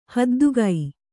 ♪ haddugai